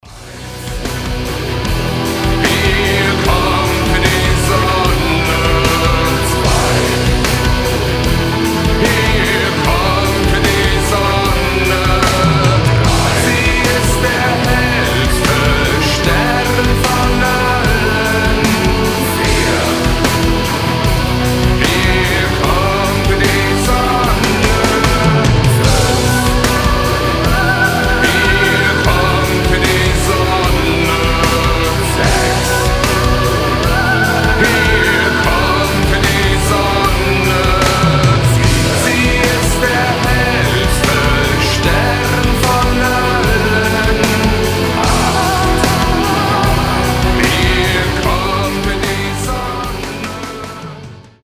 Рок рингтоны, Зарубежные рингтоны